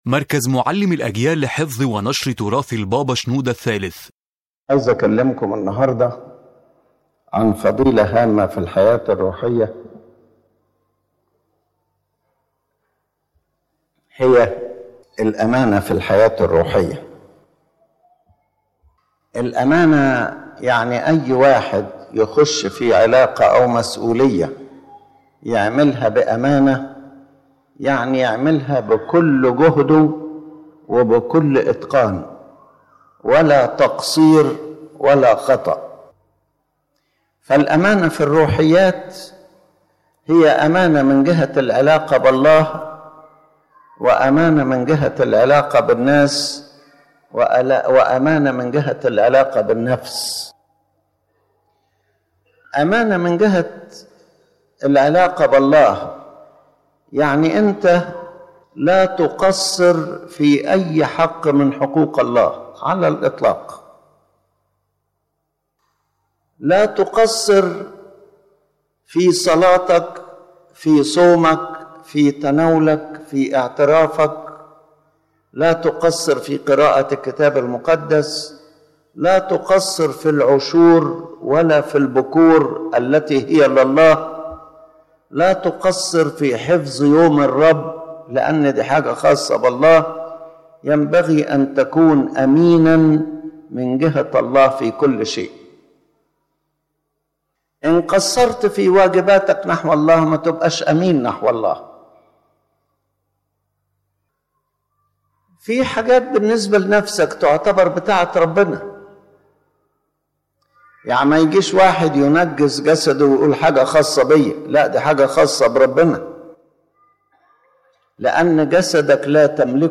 The General Idea of the Lecture